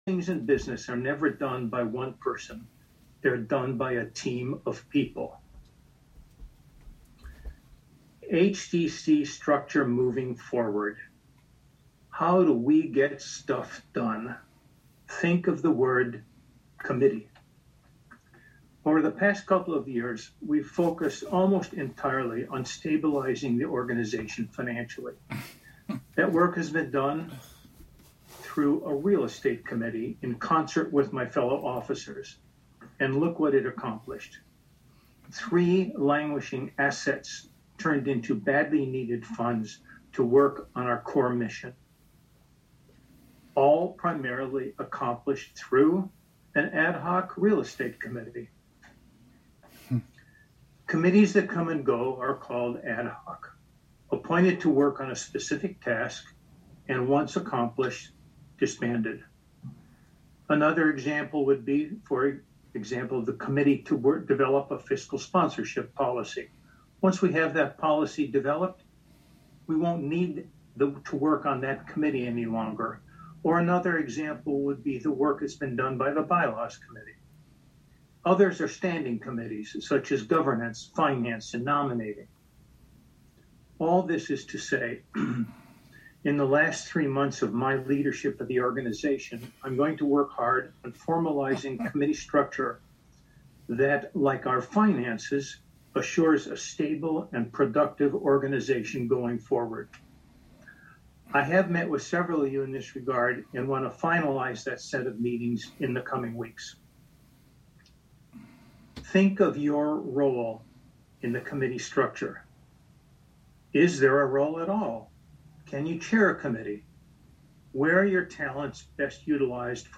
Live from the City of Hudson: Hudson Development Corp (Audio)